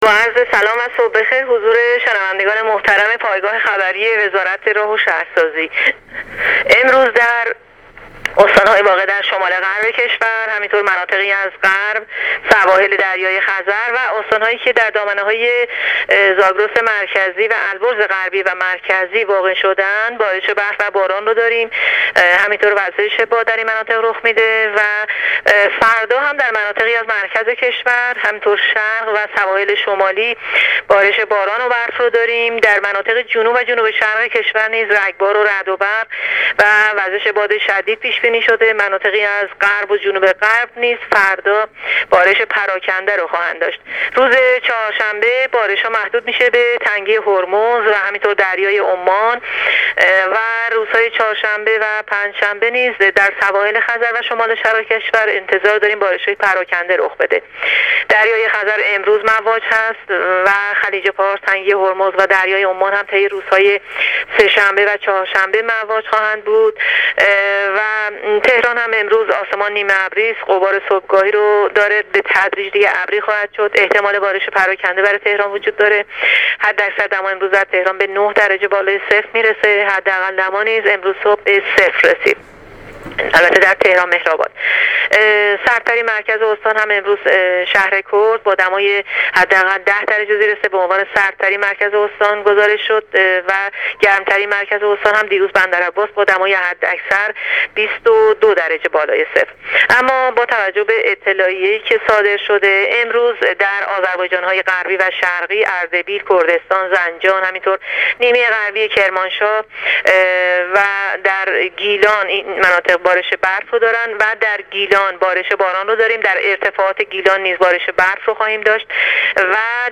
گزارش رادیو اینترنتی از آخرین وضعیت آب و هوای ۲۳ دی ۱۳۹۸